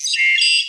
Computer3.wav